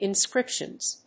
/ˌɪˈnskrɪpʃʌnz(米国英語)/